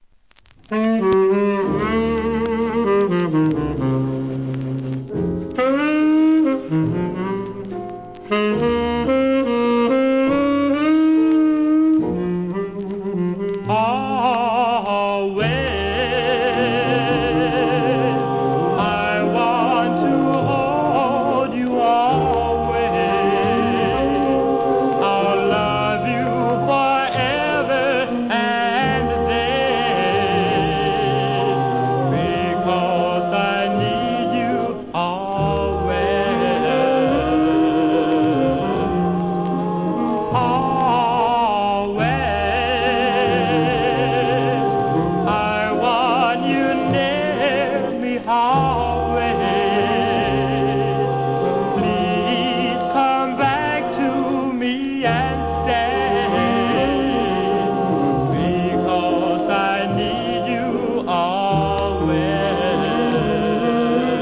店舗 数量 カートに入れる お気に入りに追加 1954年のKILLER DOO-WOPバラード!!